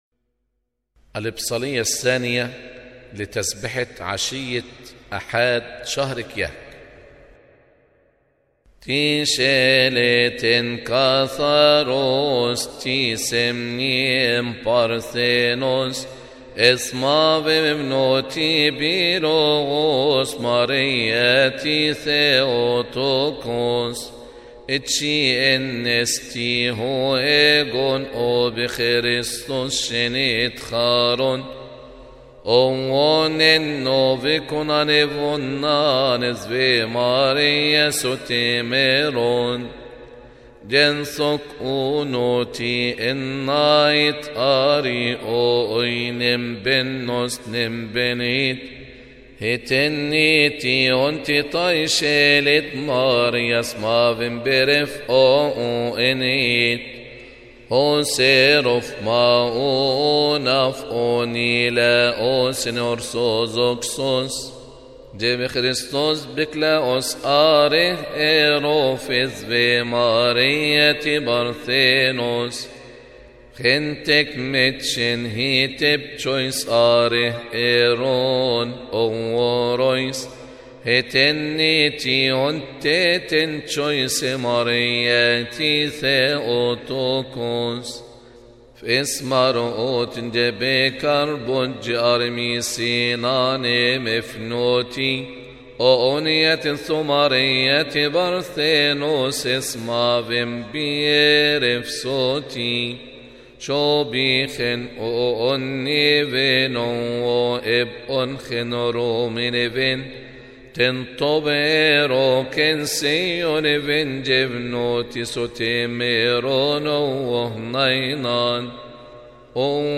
Second-Psali-Kiahk-vespers.mp3